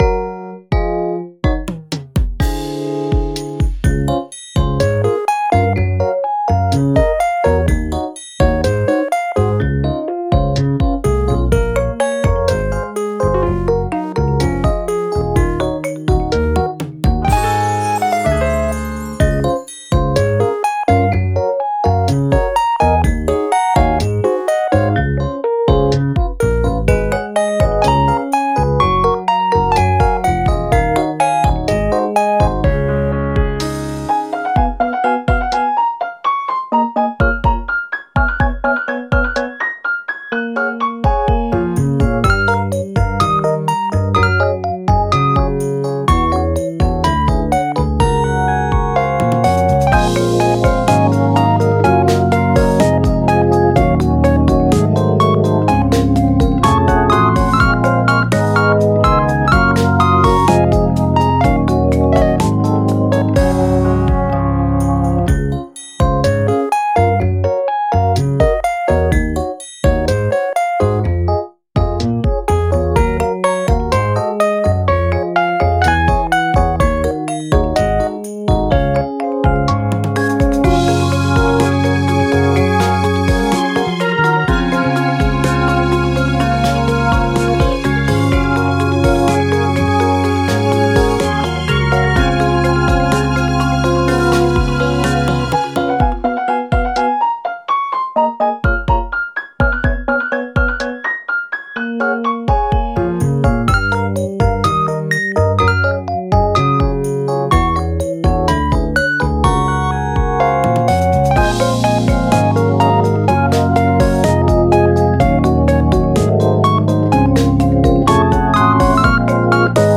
ループ用音源（BPM=125）
ループ本体